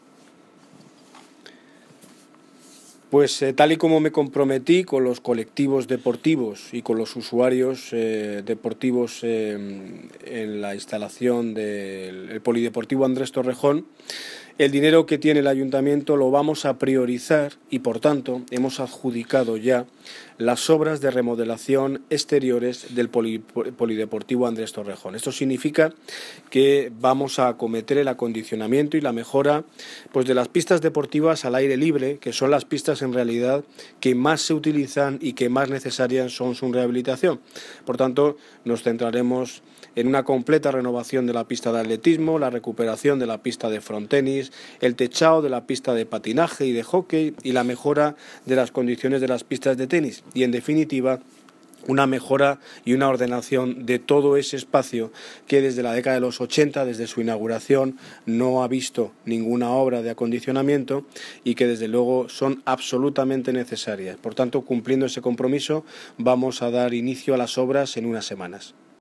Audio de Daniel Ortiz, Alcalde de Móstoles